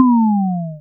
lose5.wav